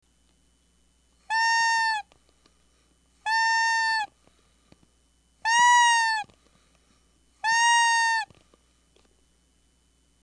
こもったような音にしたかったので口の部分にミュートのように乳酸菌飲料の容器をつけてあります。
試聴　子猫の鳴き声に聞こえるでしょうか？